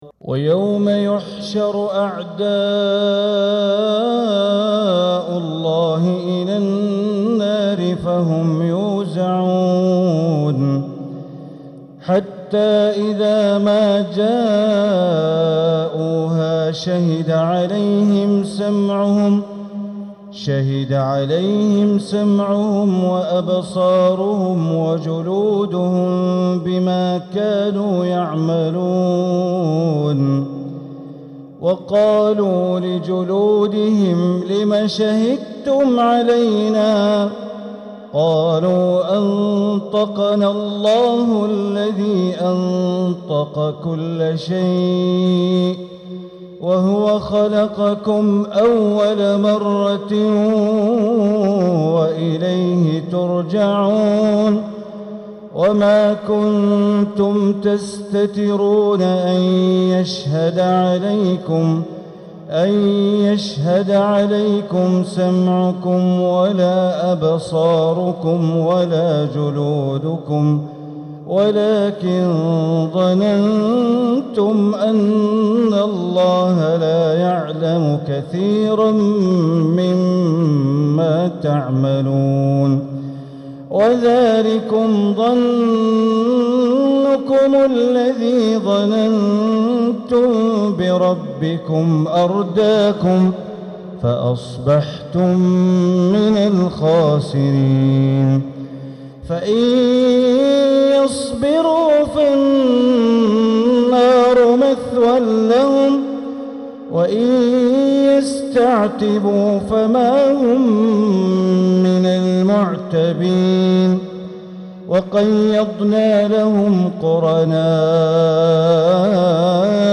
تلاوة من سورة فصلت عشاء السبت ١صفر١٤٤٧ > 1447هـ > الفروض - تلاوات بندر بليلة